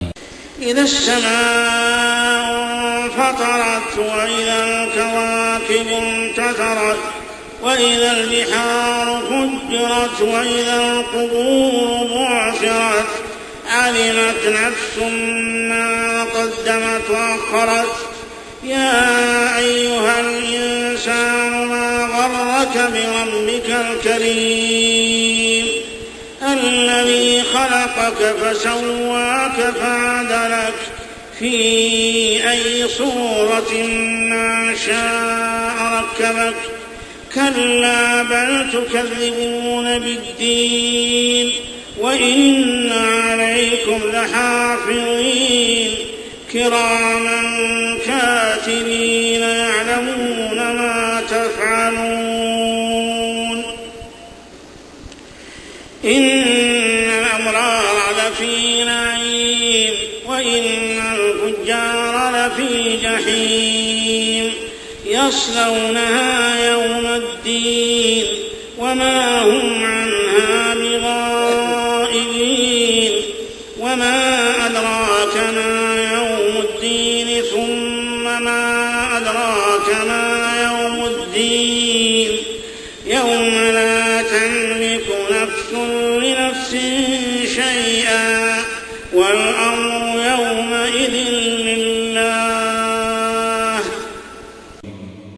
عشائيات شهر رمضان 1426هـ سورة الإنفطار كاملة | Isha prayer Surah Surah Al-Infitar > 1426 🕋 > الفروض - تلاوات الحرمين